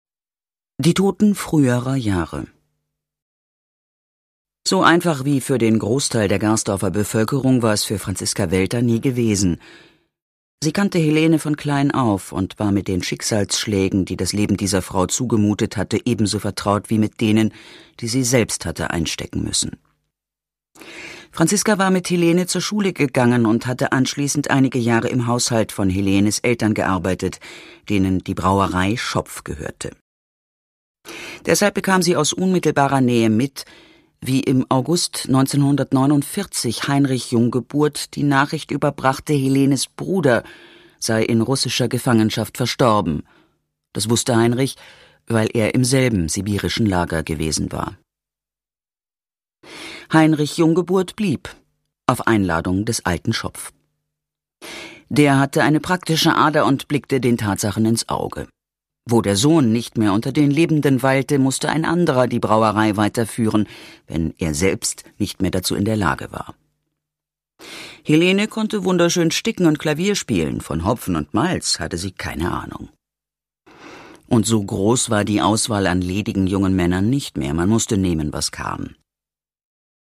Produkttyp: Hörbuch-Download
Fassung: Autorisierte Lesefassung
Gelesen von: Franziska Pigulla